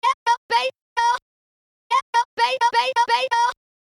标签： 112 bpm House Loops Vocal Loops 526.54 KB wav Key : Unknown
声道立体声